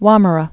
(wŏmər-ə)